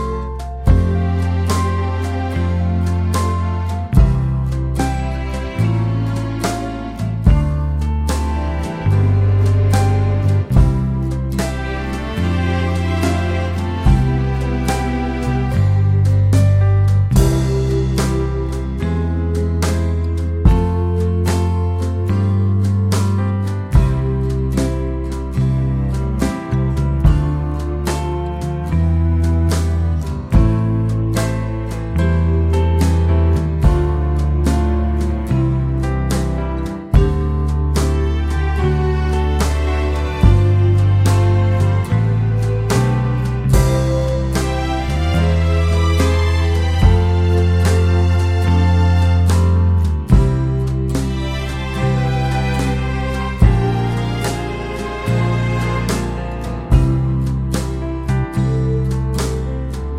no Backing Vocals Easy Listening 4:38 Buy £1.50